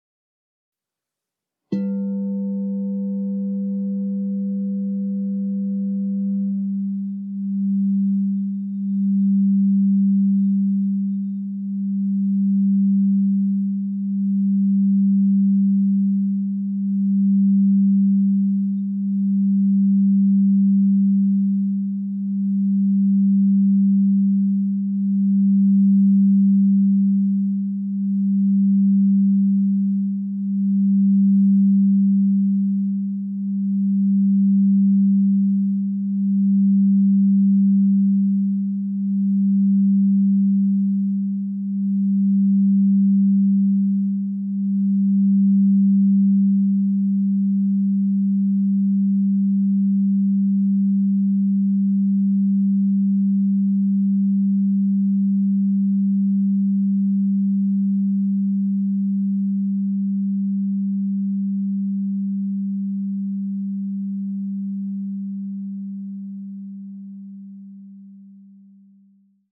Meinl Sonic Energy 12" white-frosted Crystal Singing Bowl G3, 440 Hz, Throat Chakra (CSBM12G3)
Product information "Meinl Sonic Energy 12" white-frosted Crystal Singing Bowl G3, 440 Hz, Throat Chakra (CSBM12G3)" The white-frosted Meinl Sonic Energy Crystal Singing Bowls made of high-purity quartz create a very pleasant aura with their sound and design.